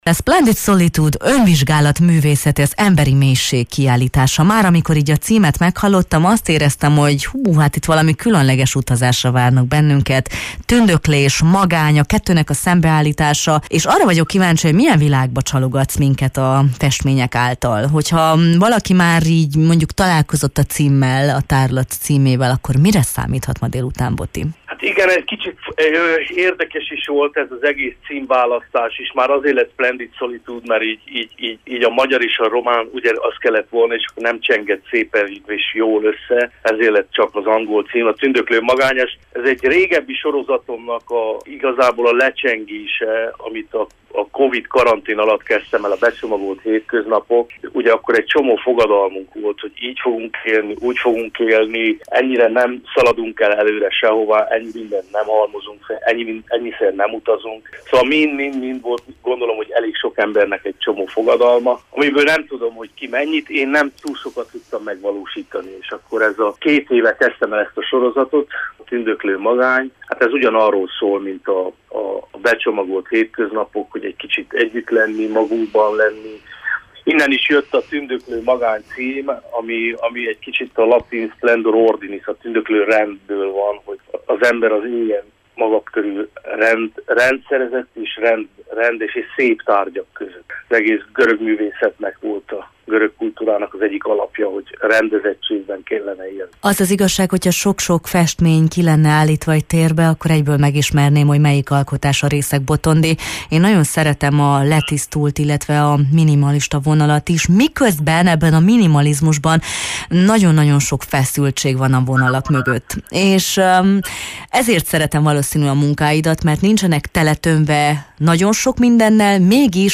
beszélgettünk a Jó reggelt, Erdély!-ben: